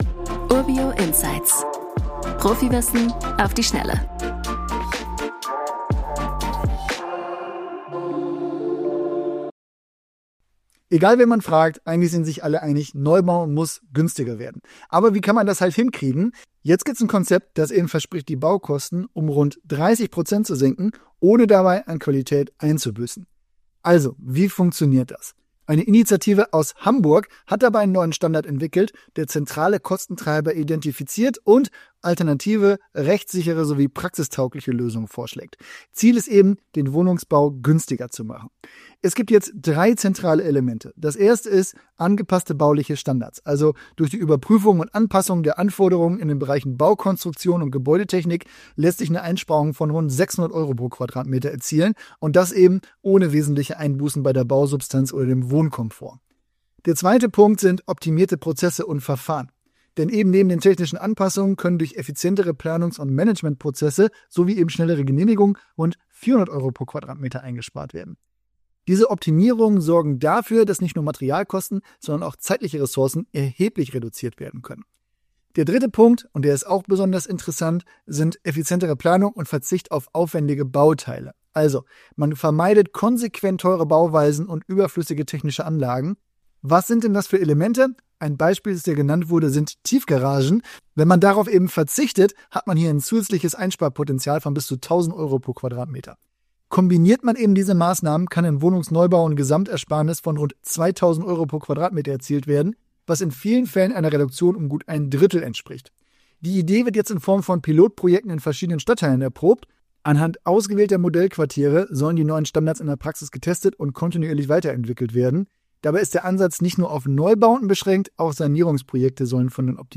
Experten aus Wirtschaft und Verwaltung